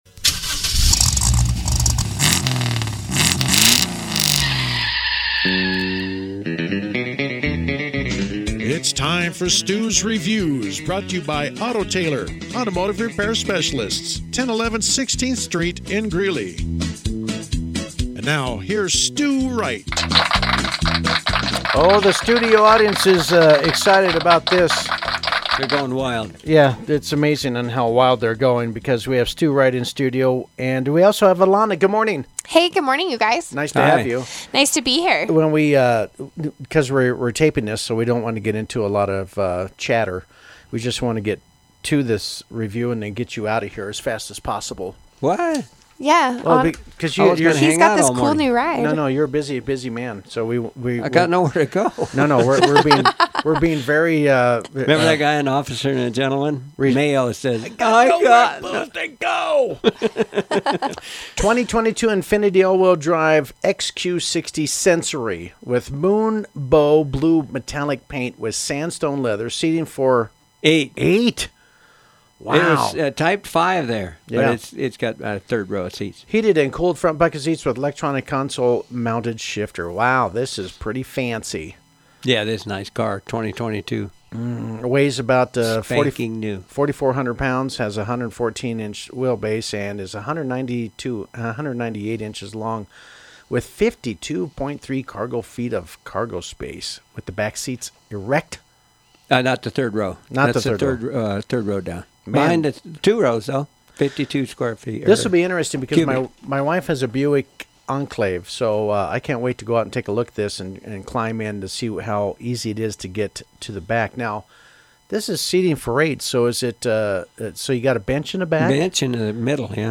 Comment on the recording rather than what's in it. were instrumental in reviewing the QX-60 outside the Pirate Radio studios: